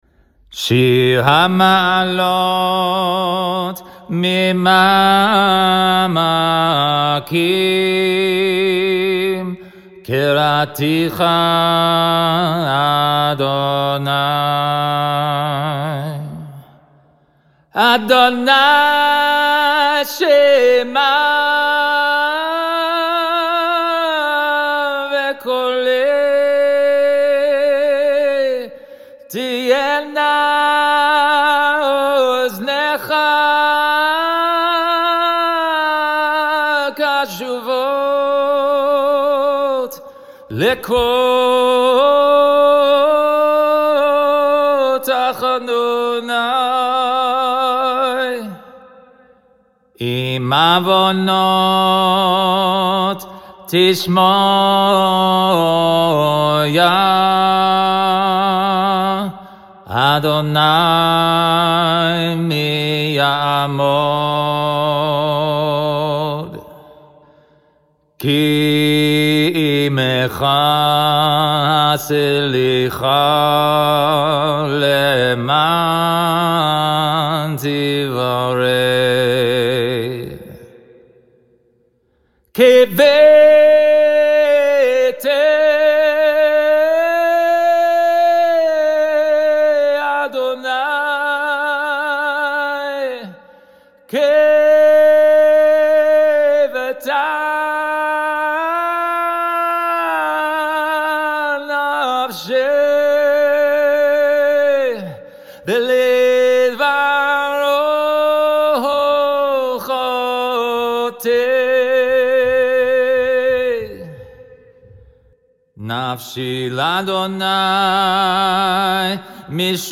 During the Ten Days, we recite Psalm 130 as a call and response, verse by verse, before the Barechu and the morning Shema. This audio features a Chazan's melody for Psalm 130, verse by verse.